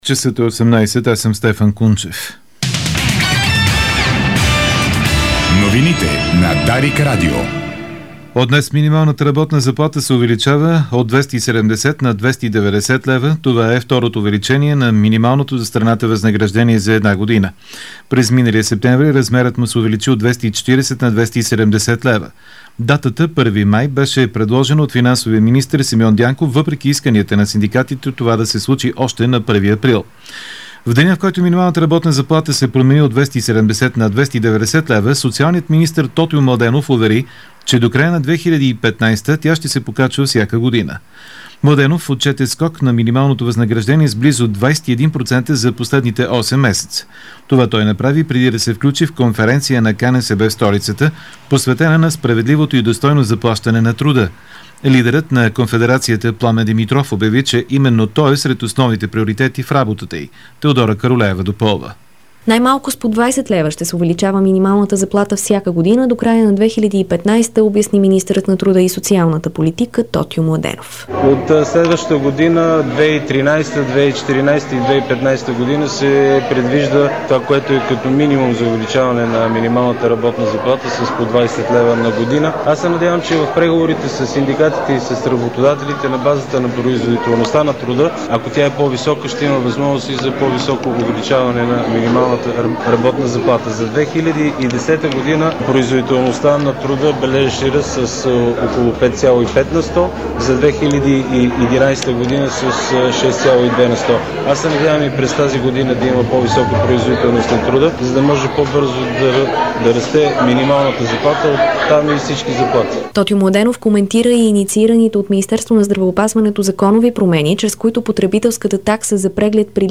Обзорна информационна емисия - 01.05.2012